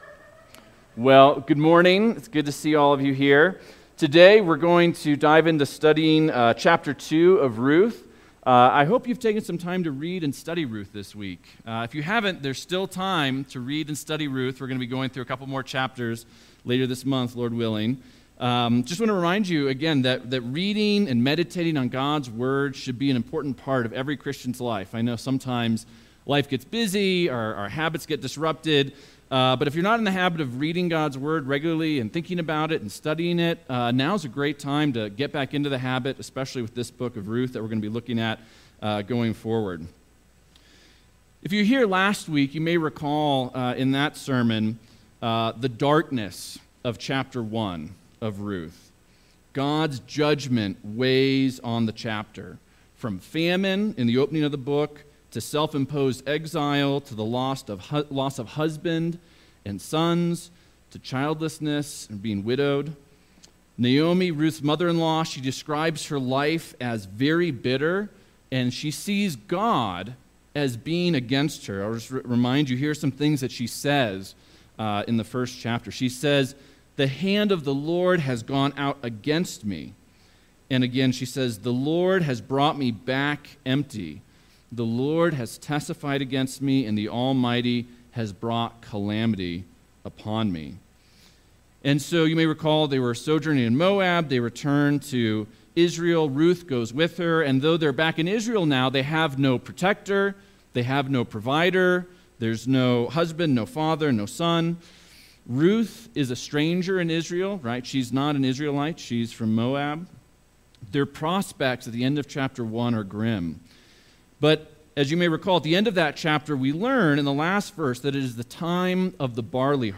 Sermons | First Baptist Church of Leadville